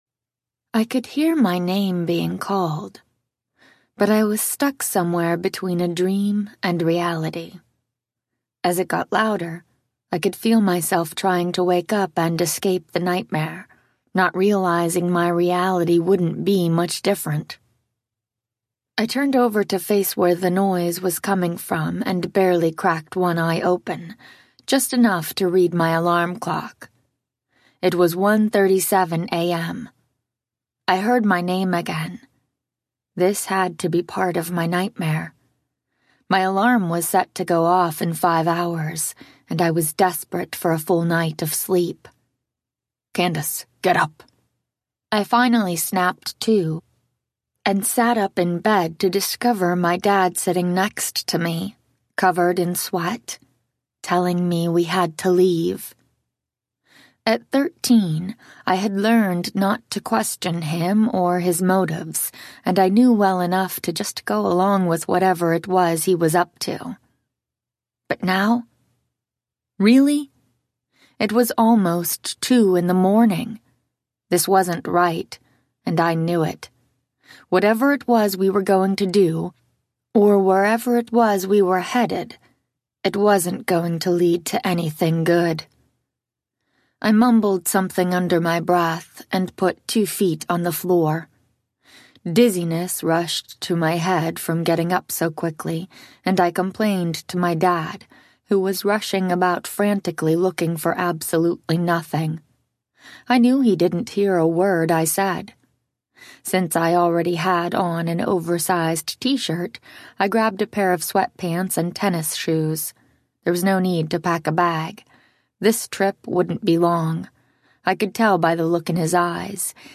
The Con Man’s Daughter Audiobook
Narrator
5.5 Hrs. – Unabridged